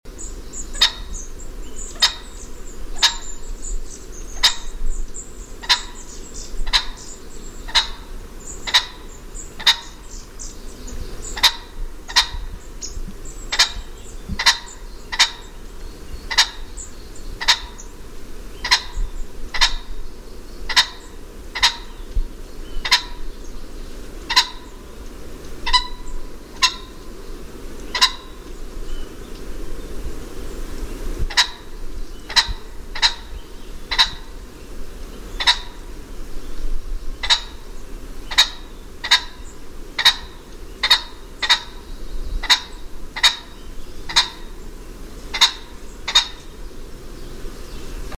Звуки фазана
На этой странице собраны разнообразные звуки фазанов – от характерного квохтания до резких тревожных криков.